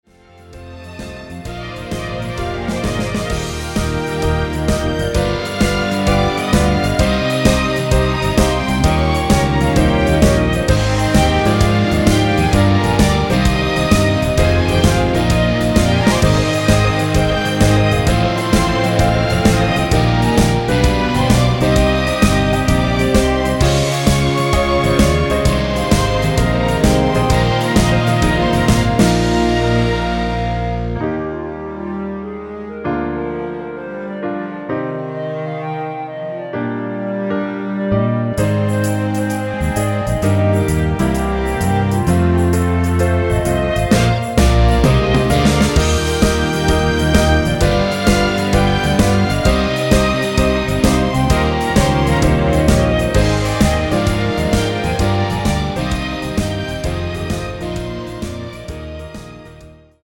전주 없는 곡이라 전주 2마디 만들어 놓았습니다.
엔딩이 페이드 아웃이라 라이브 하시기 편하게 엔딩을 만들어 놓았습니다
1절후 2절 없이 후렴으로 진행 됩니다.(본문 가사 참조)
◈ 곡명 옆 (-1)은 반음 내림, (+1)은 반음 올림 입니다.
멜로디 MR이라고 합니다.
앞부분30초, 뒷부분30초씩 편집해서 올려 드리고 있습니다.